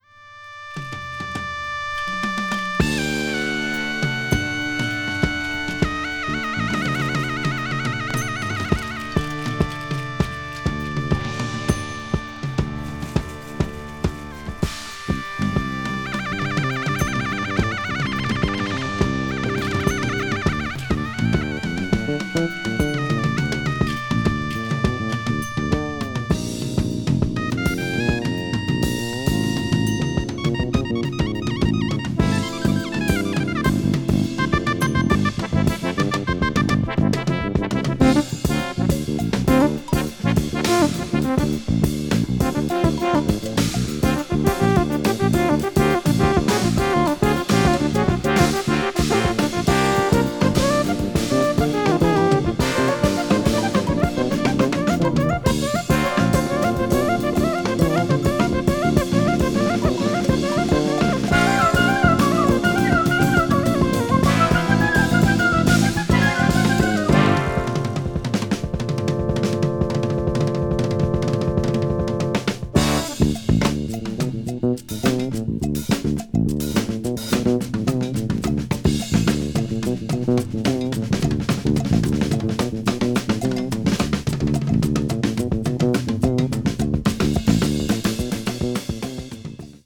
contemporary jazz   crossover   fusion   post bop